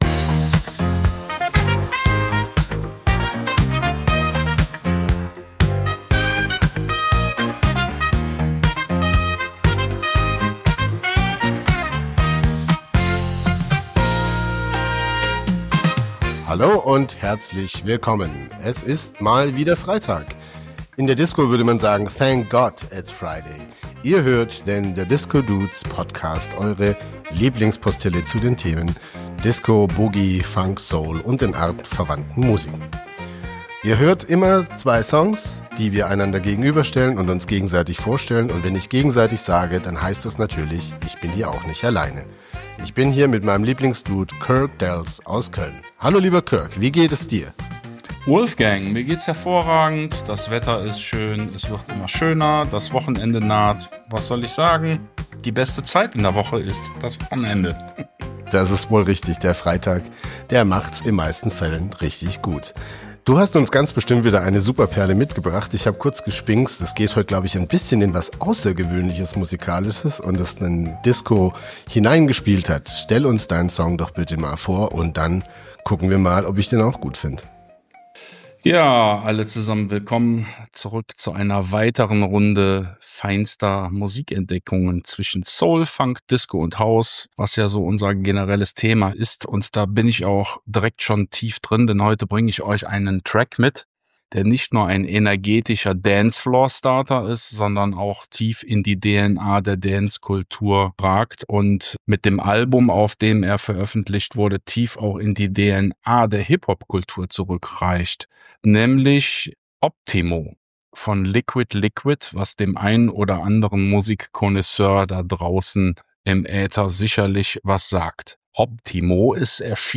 Proto Post Punk Disco meets a Englishman in Philly Sound